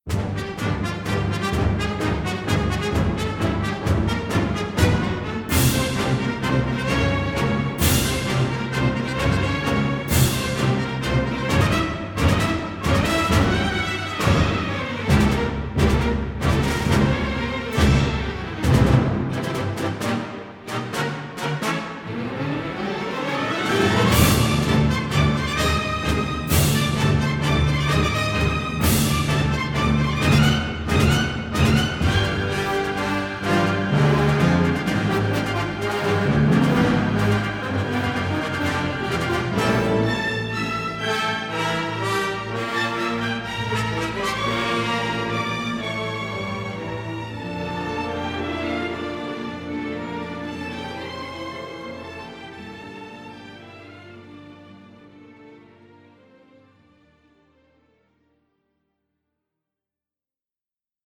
symphonic score
unabashedly romantic
suspenseful and brooding, pastoral and uplifting